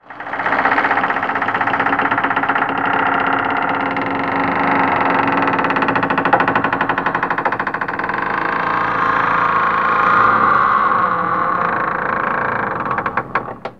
CREAK 4 -02L.wav